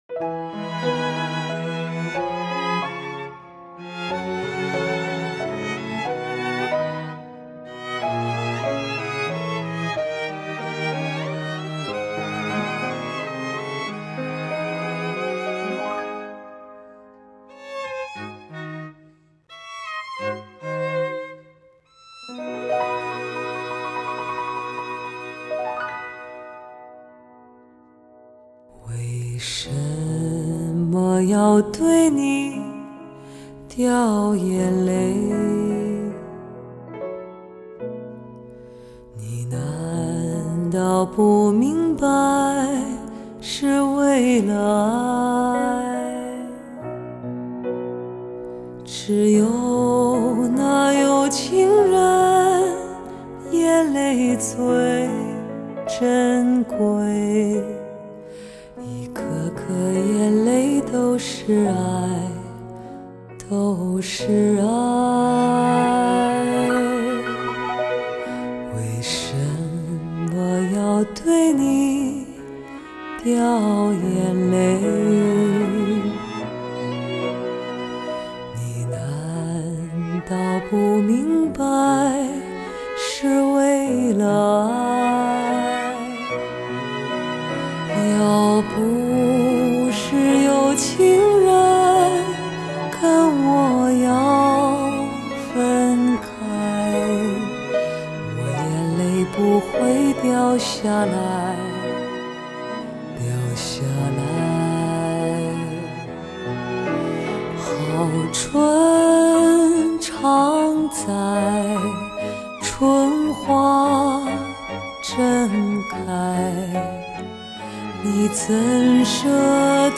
精品发烧试音碟